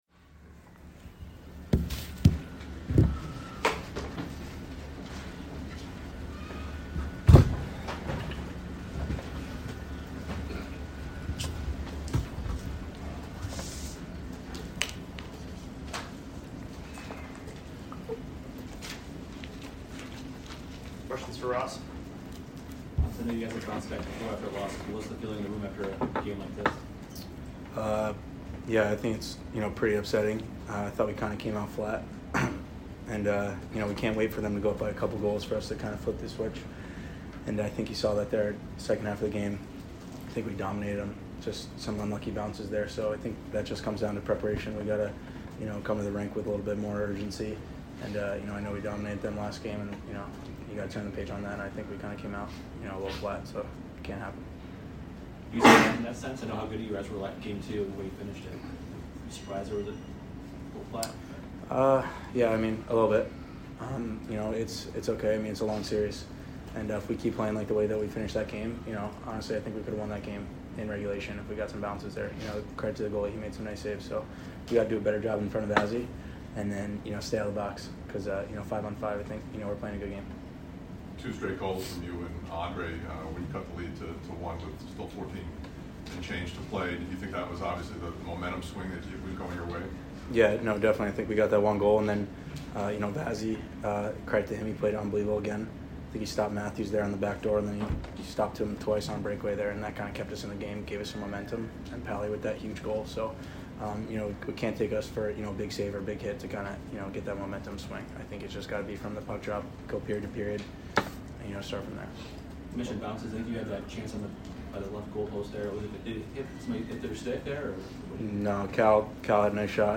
Ross Colton Post Game Vs TOR 5 - 6-2022